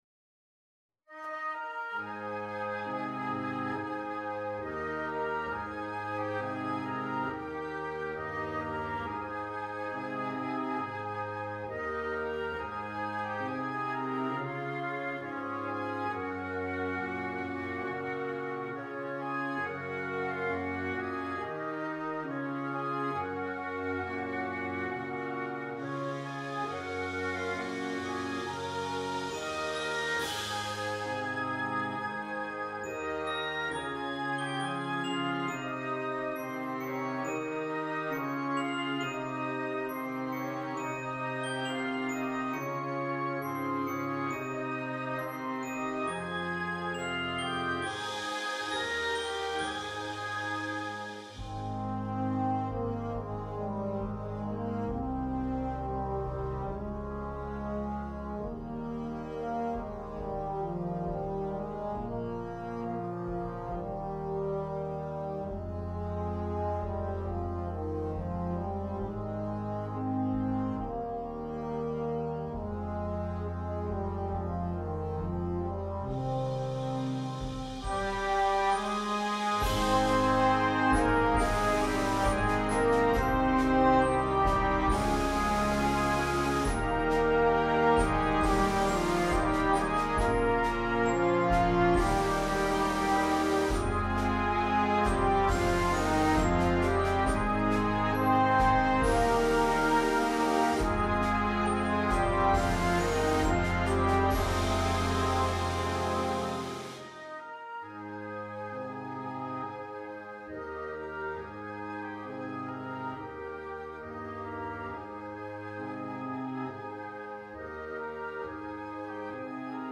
is a study in tone color and contrast.